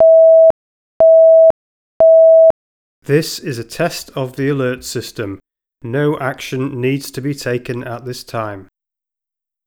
Commercial Audio are able to design and supply audio system components which can replace or work alongside your existing communication systems to provide site wide initiation of a lockdown in response to a threat as well as voice announcements to confirm the status of the emergency to all occupants.
Test Message